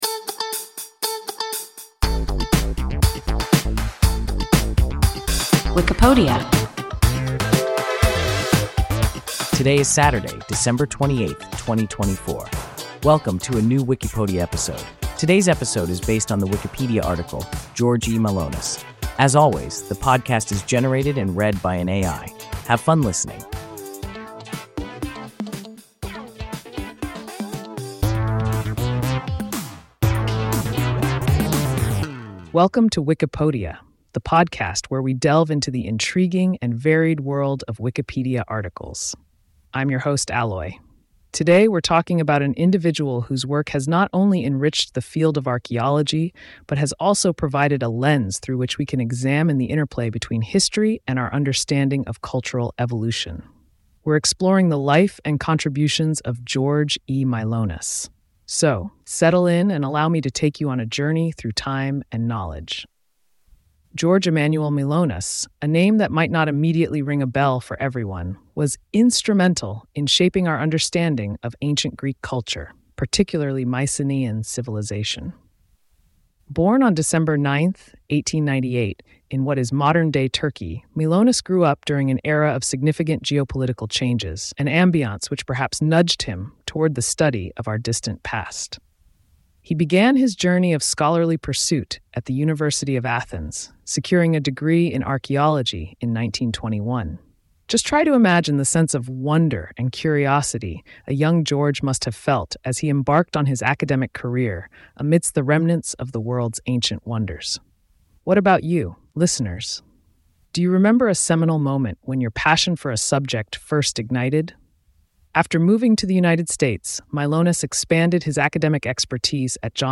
George E. Mylonas – WIKIPODIA – ein KI Podcast